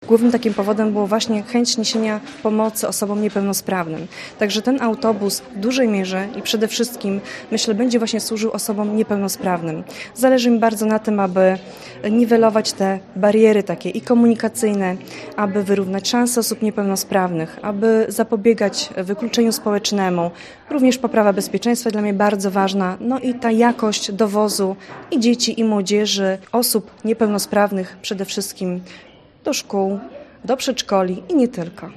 O złożonym wniosku mówi Anna Katarzyna Larent, Wójt Gminy Olszewo-Borki: